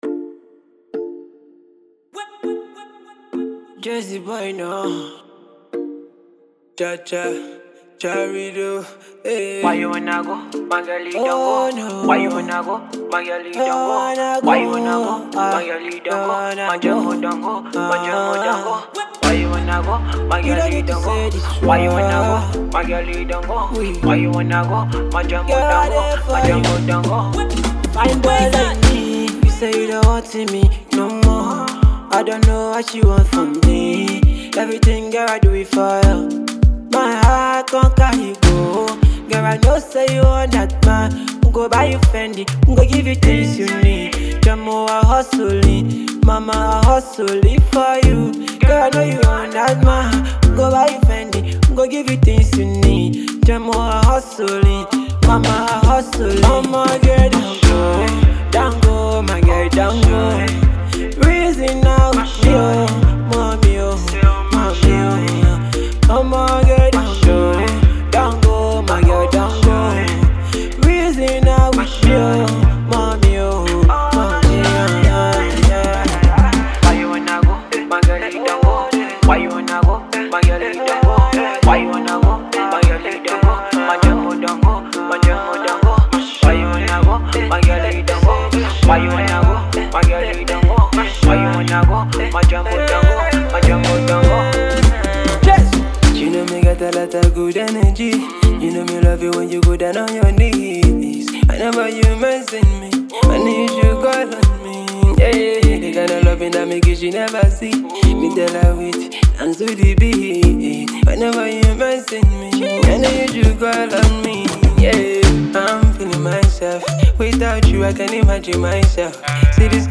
the rising Afrobeat artist